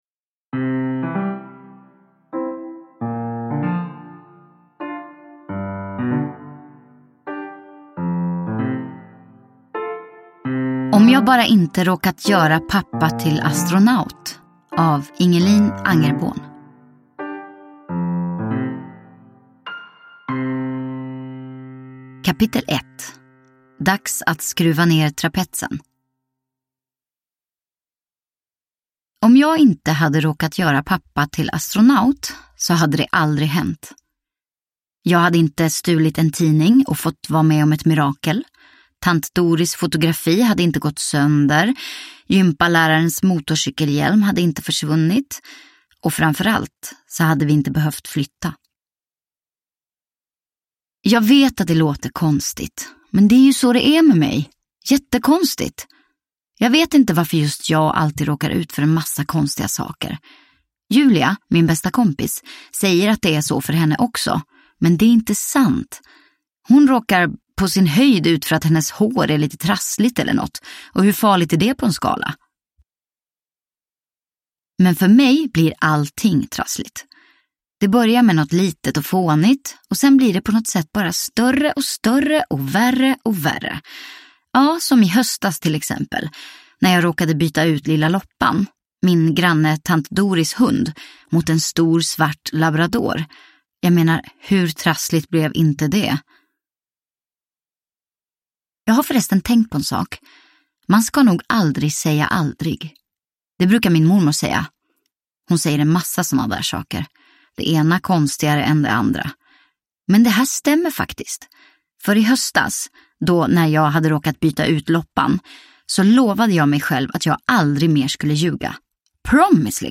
Om jag bara inte råkat göra pappa till astronaut – Ljudbok – Laddas ner